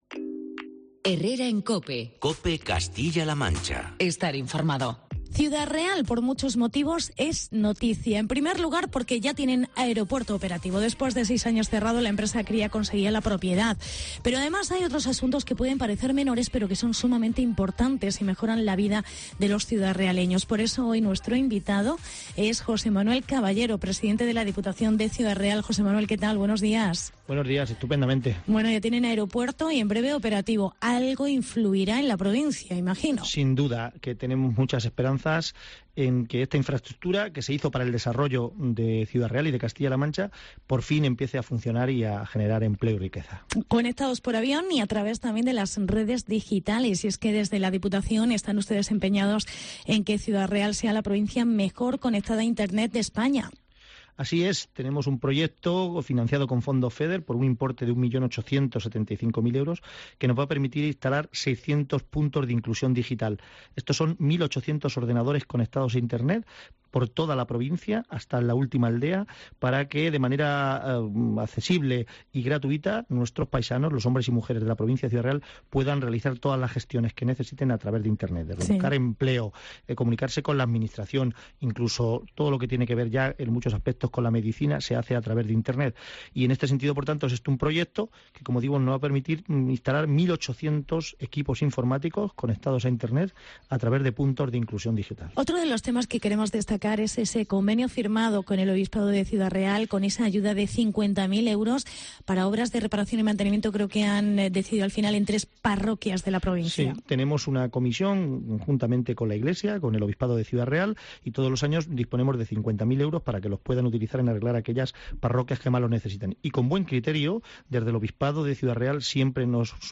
Entrevista con el Presidente de la Diputación de Ciudad Real: José Manuel Caballero